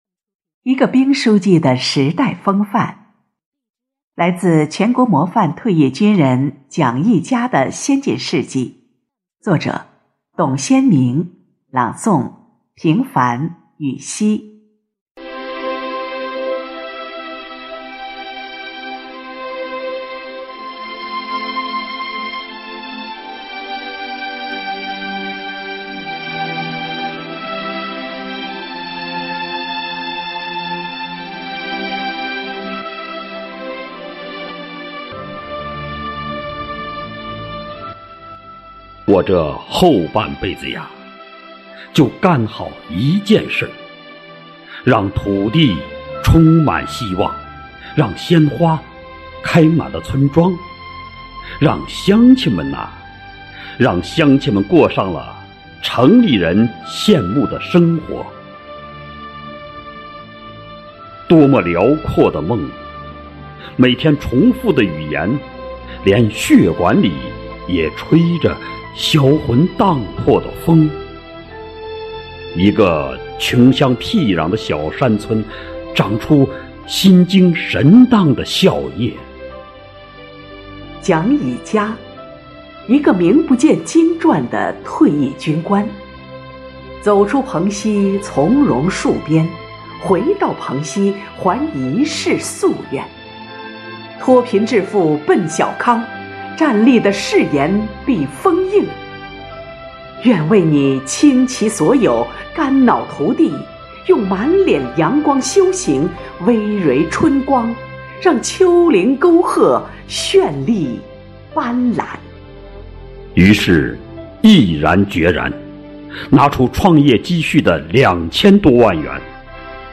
朗誦